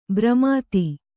♪ bramāti